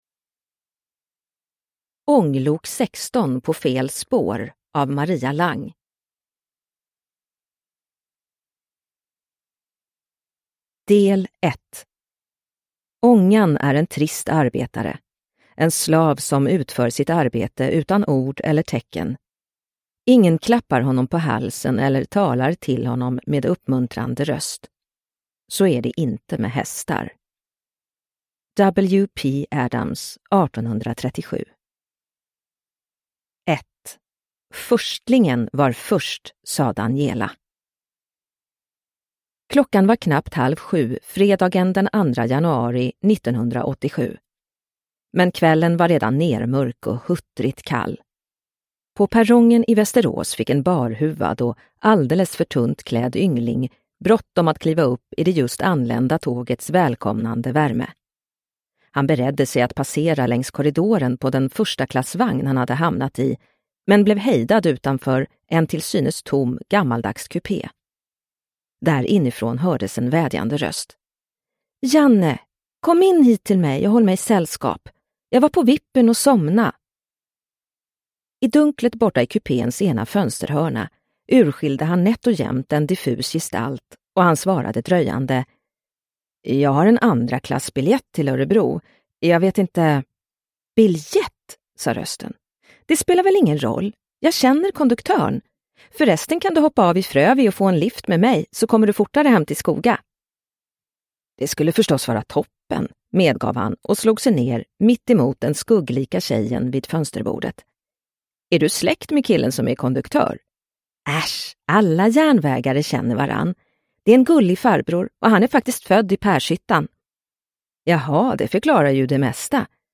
Ånglok 16 på fel spår – Ljudbok – Laddas ner